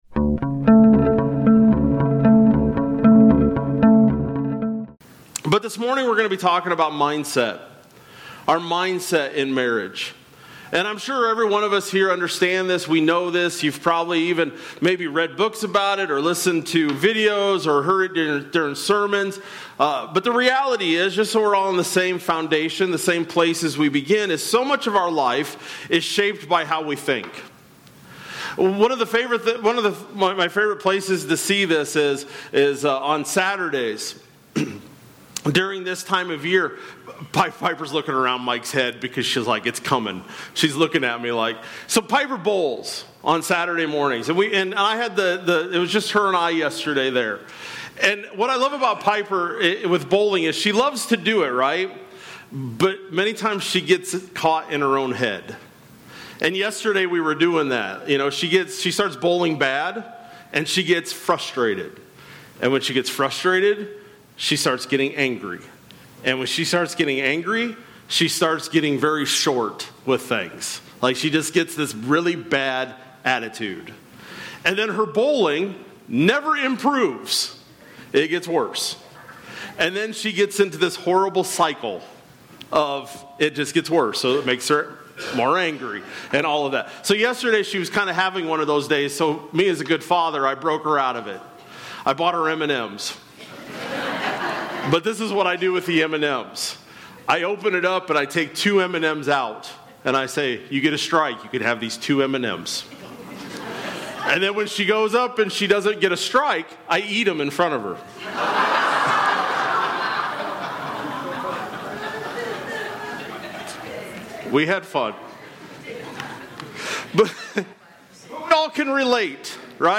Oct-5-25-Sermon-Audio.mp3